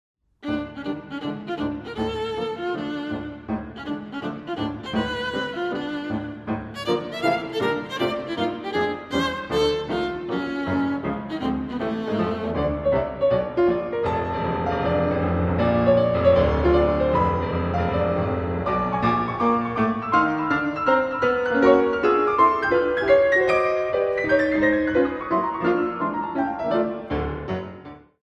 Suite for viola and piano op. 102a (World Premiere)